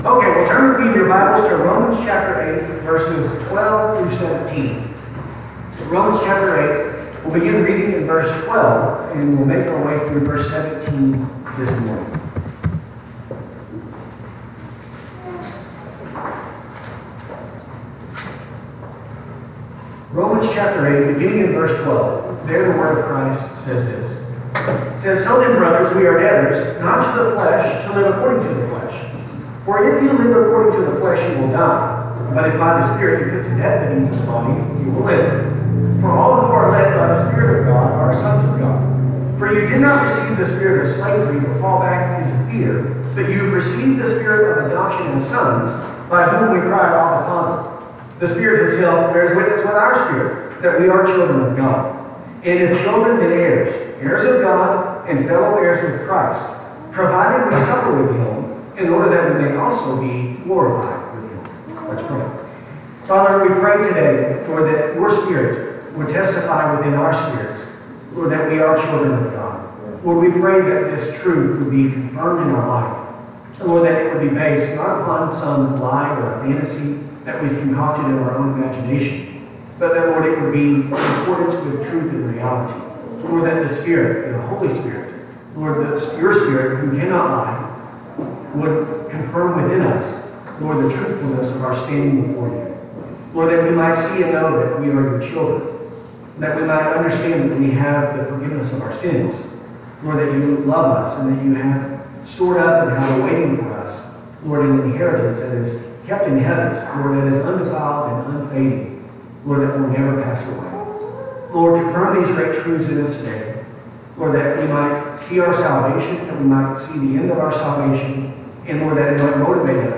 Due to technical issues the audio recording of this sermon is not clear and will require a higher than normal listening volume.